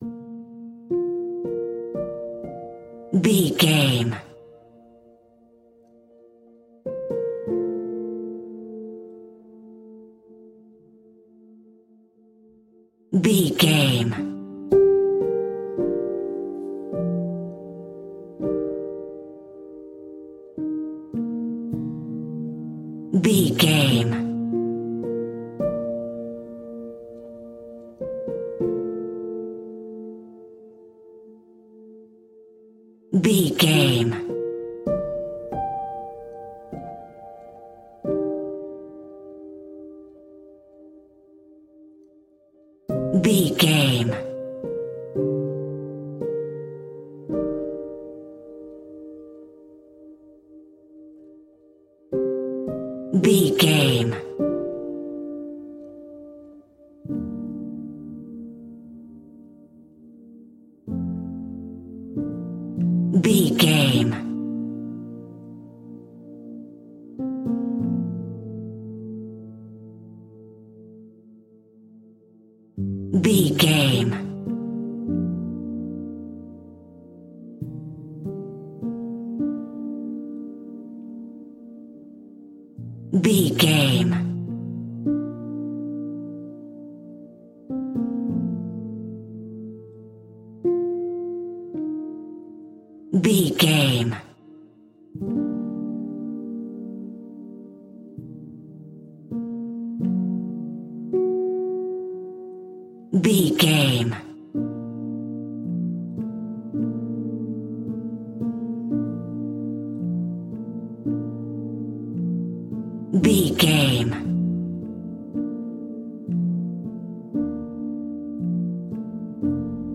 Ionian/Major
B♭
romantic
soft
piano